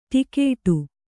♪ ṭikēṭu